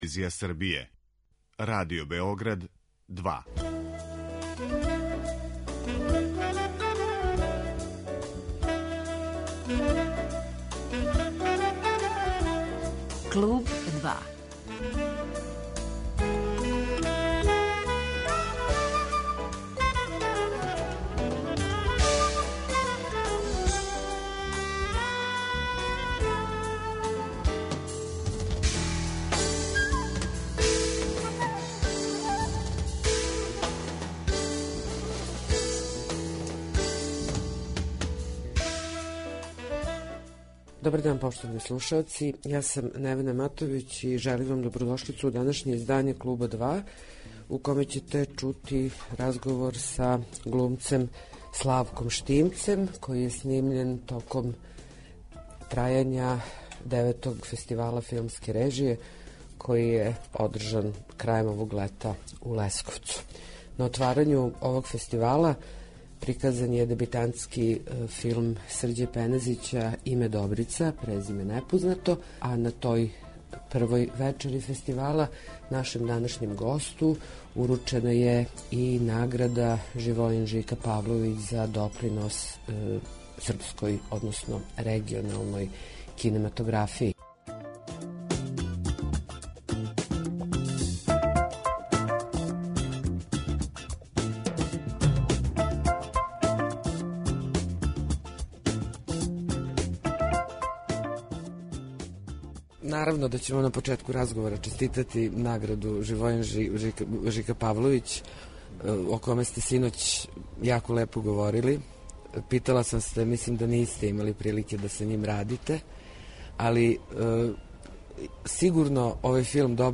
Разговор који ћете чути снимљен је у Лесковцу, на Фестивалу филмске режије , где је филм имао једну од првих пројекција; приказан је у оквиру такмичарског програма, на отварању фестивала.